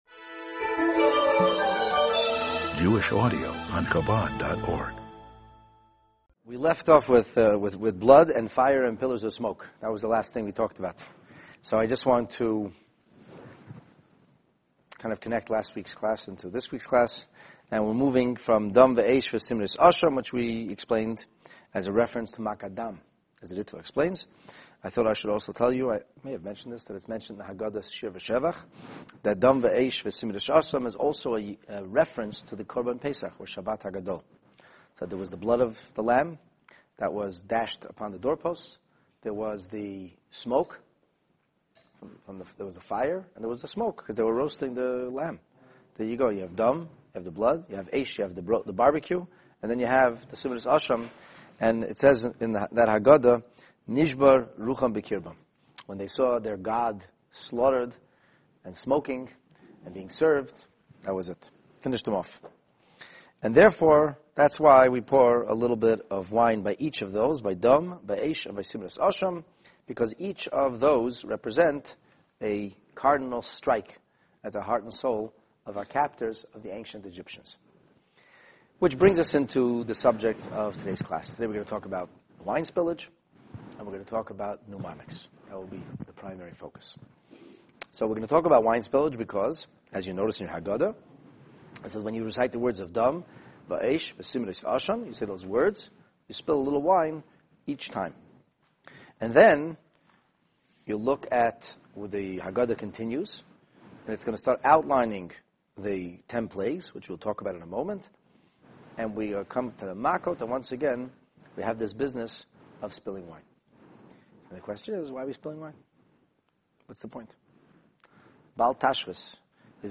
The Haggadah in Depth Part 14 This inspiring Haggadah class introduces you to the unusual custom of wasted wine intentionally spilled from your cup when mentioning the proverbial Ten Plagues. Discover the different reasons for, and the methods of this strange spillage (learn why wasting good wine, can actually be a good idea?!), and finally see why everybody would be best off “Going Kabbalistic” at this juncture in the Seder!